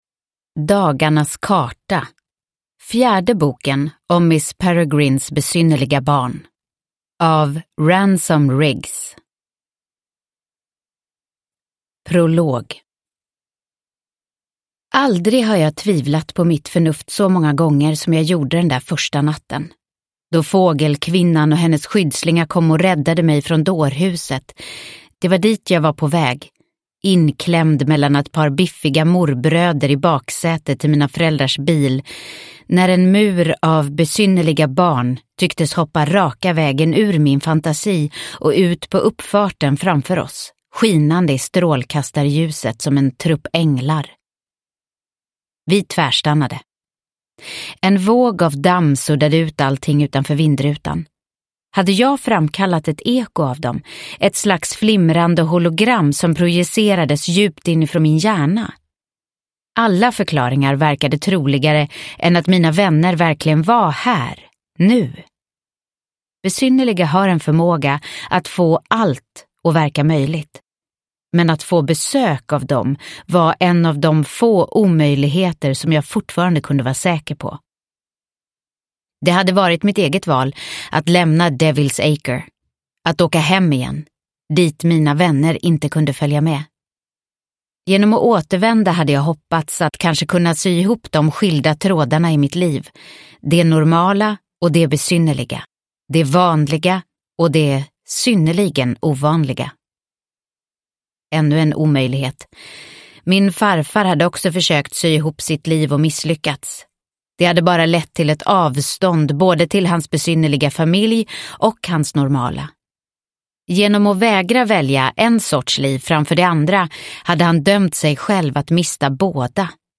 Dagarnas karta – Ljudbok – Laddas ner